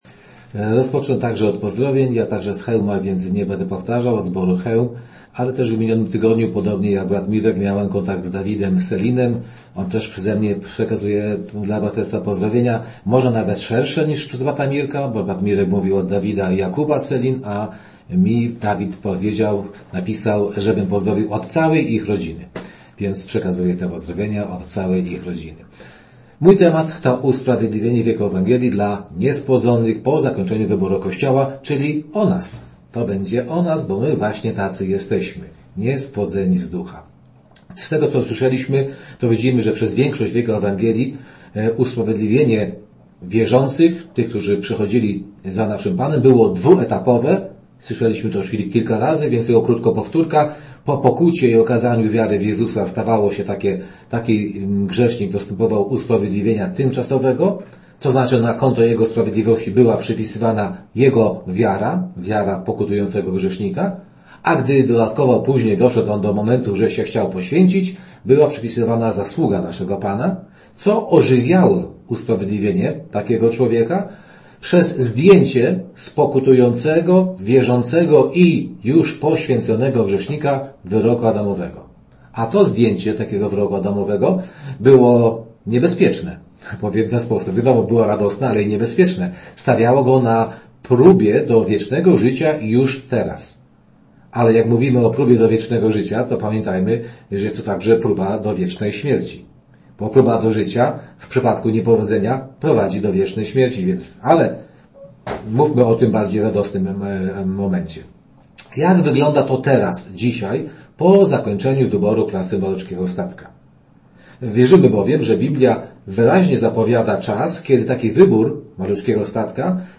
Wykłady